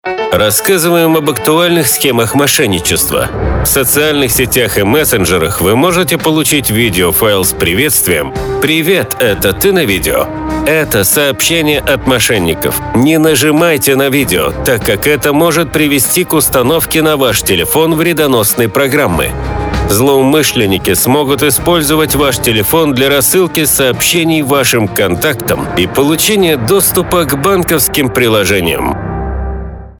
Социальная реклама, направленная на противодействие преступлениям, совершаемым с использованием информационно-телекоммуникационных технологий.